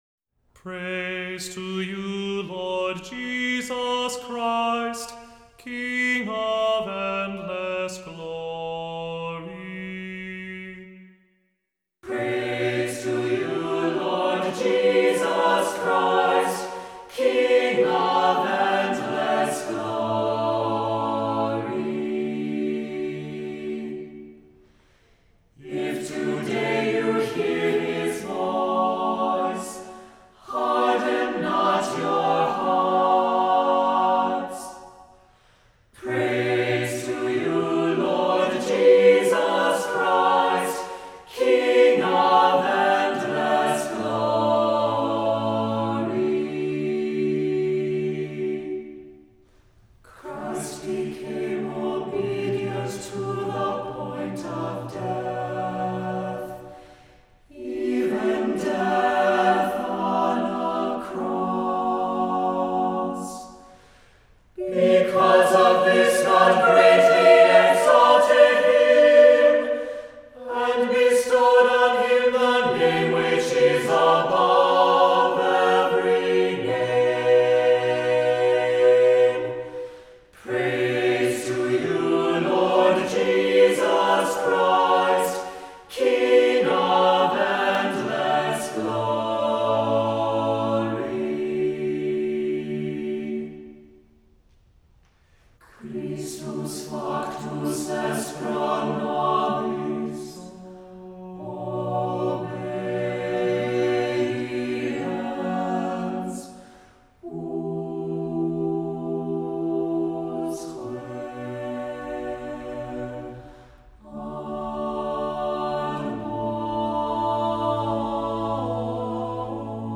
Voicing: Assembly,a cappella,Cantor,SATB